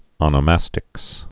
(ŏnə-măstĭks)